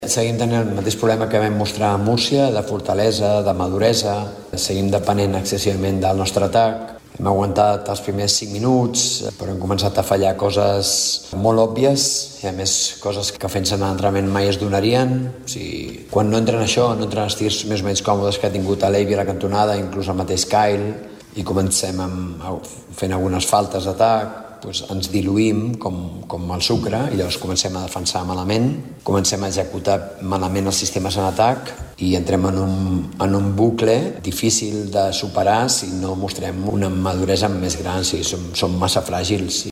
Els canaris han dominat de principi a fi i han deixat els tricolors sense resposta, que acumulen així la seva segona derrota consecutiva en dues jornades per a 64-96. Ho ha explicat el tècnic, Joan Plaza.